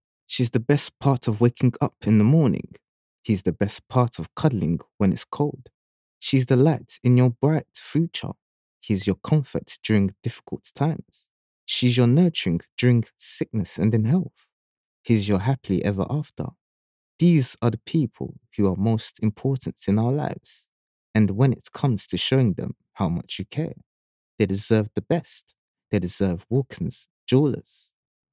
British English, male, warm, mature, assured, rich, friendly, baritone.
Home studio.